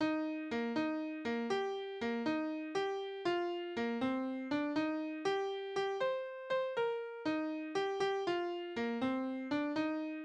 Balladen: Das überraschte Liebespaar
Tonart: Es-Dur
Tonumfang: große None
Besetzung: vokal
Anmerkung: Die Taktart ist nicht eindeutig, da die Takte unterschiedlich lang sind. Aber es handelt sich wahrscheinlich um einen ungeraden Achteltakt (3/8 oder 6/8).